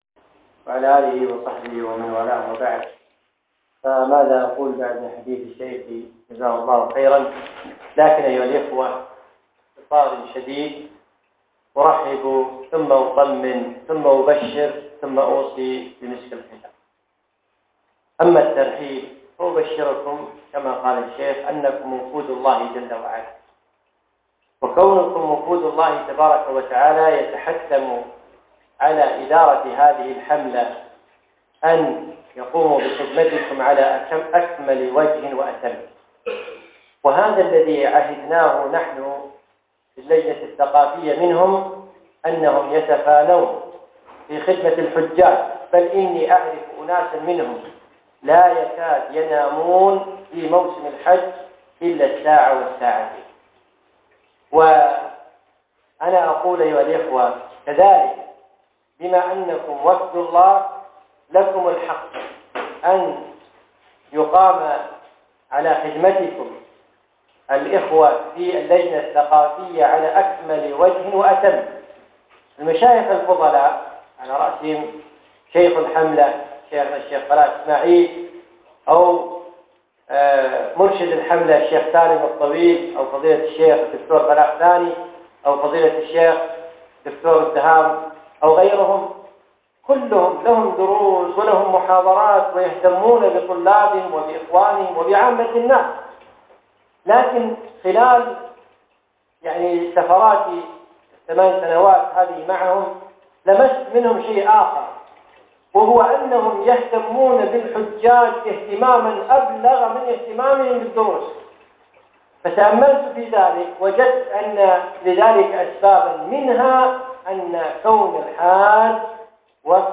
الإستعداد للحج - كلمة في ملتقى حجاج حملة مندكار